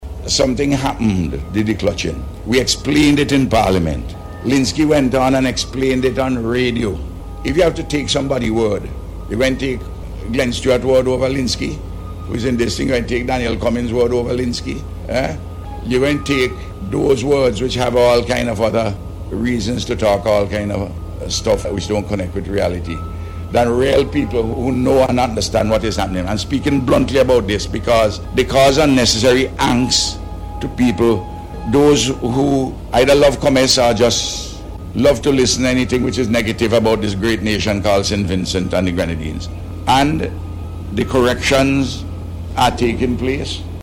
The Prime Minister made this appeal as he spoke to the Agency for Public Information during a tour of the facility yesterday.